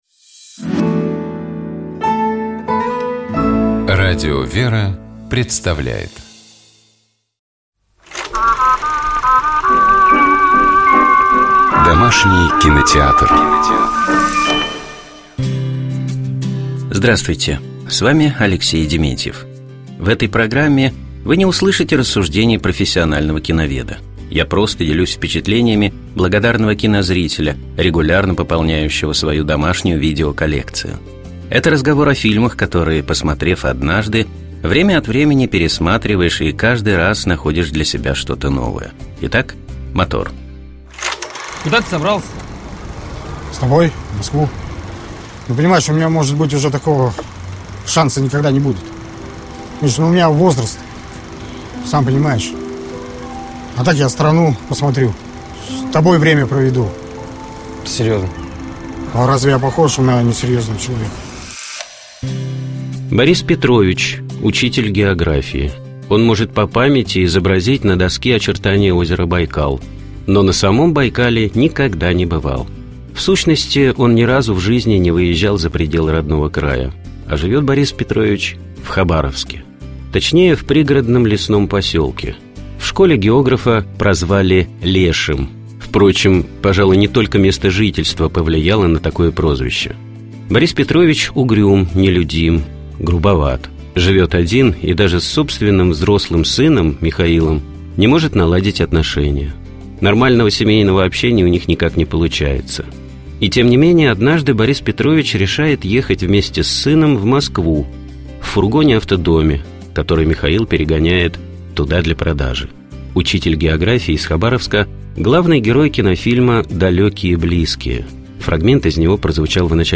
Фрагмент из него прозвучал в начале нашей программы.